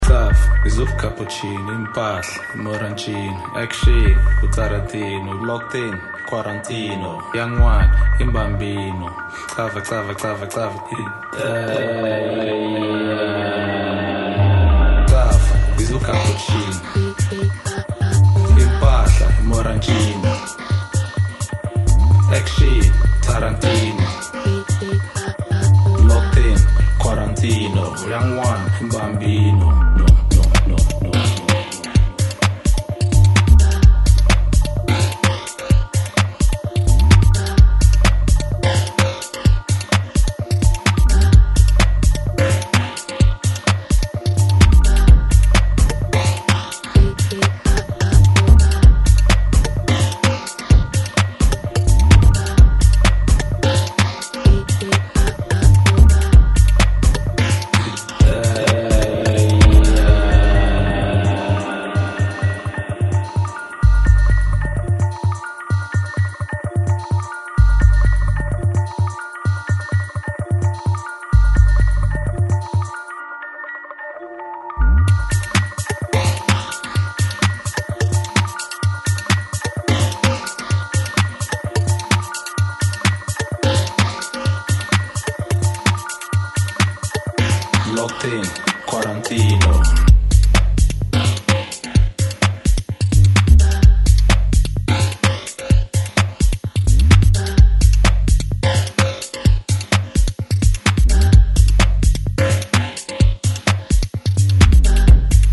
acid sounds